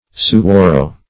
Search Result for " suwarrow" : The Collaborative International Dictionary of English v.0.48: Suwarrow \Su*war"row\, n. (Bot.) The giant cactus ( Cereus giganteus ); -- so named by the Indians of Arizona.